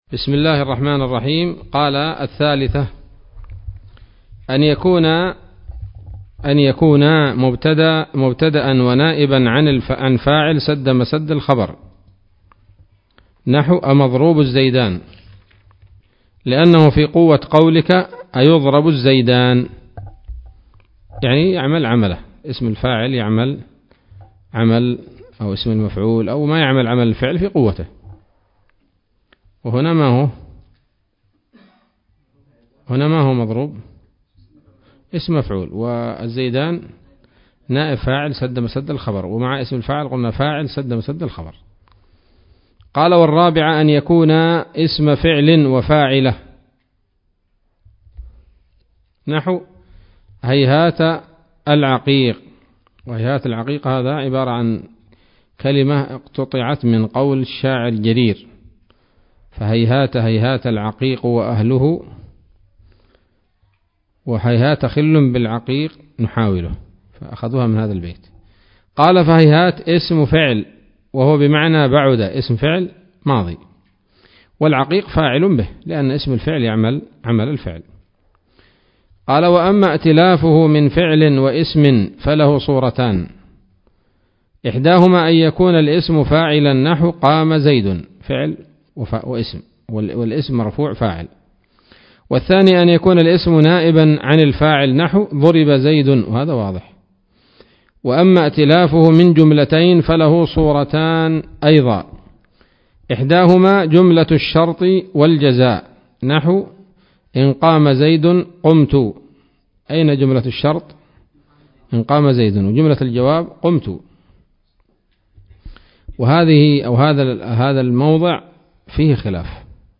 الدرس الرابع عشر من شرح قطر الندى وبل الصدى [1444هـ]